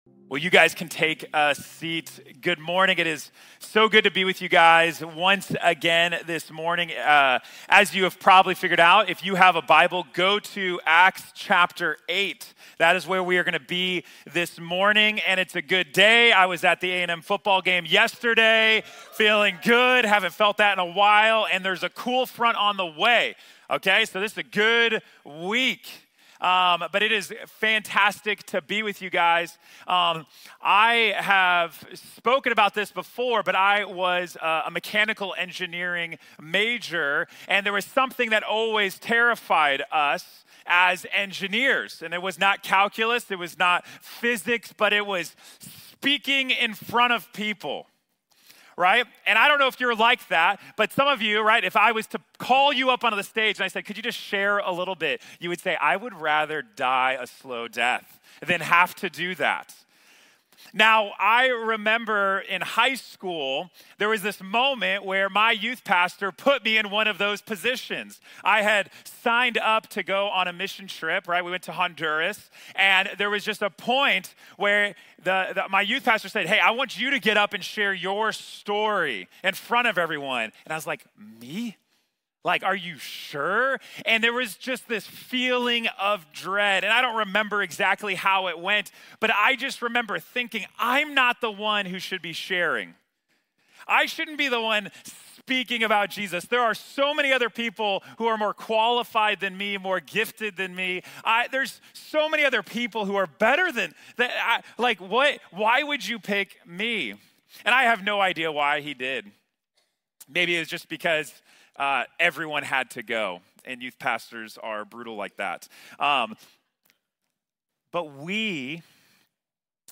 To The Ends of The Earth | Sermon | Grace Bible Church